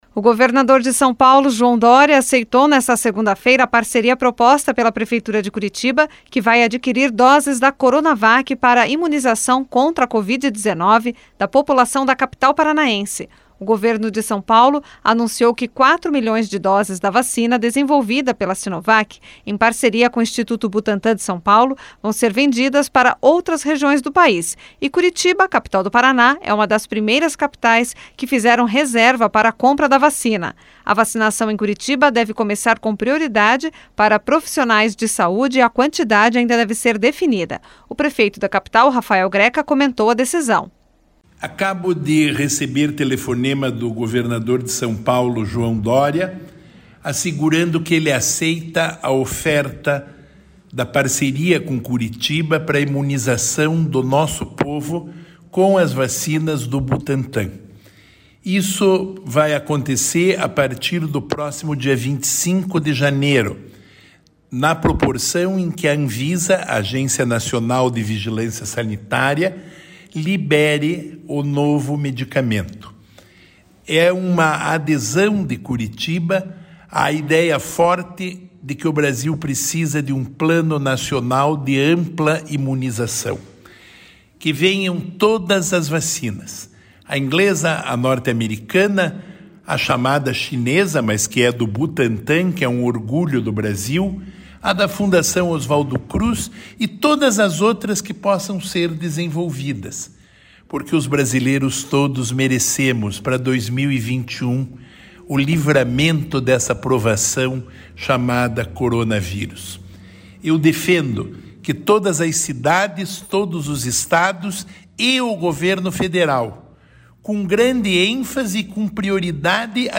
O prefeito da capital, Rafael Greca, comentou a decisão.
Repórter